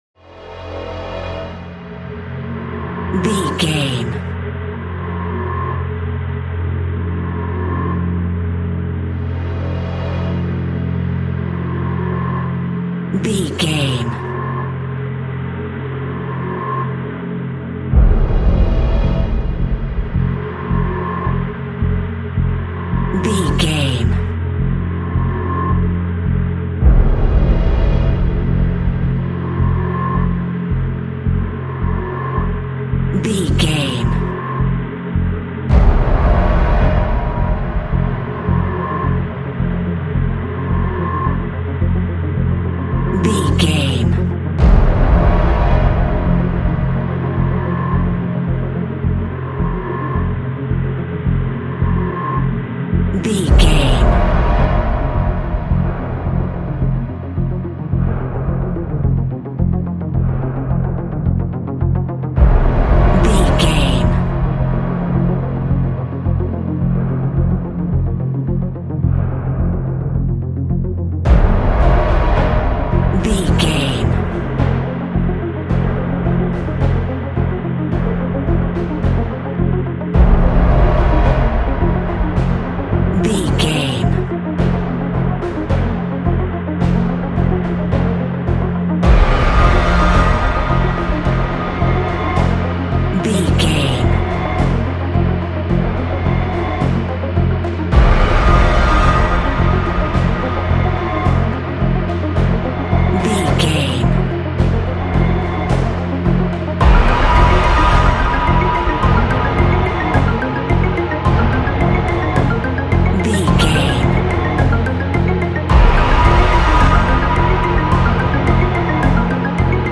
Aeolian/Minor
ambient
atmospheric
dissonant
eerie
ominous
suspenseful